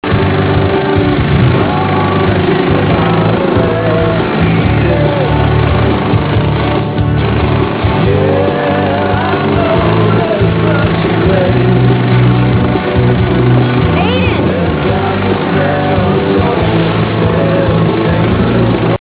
Comment: rock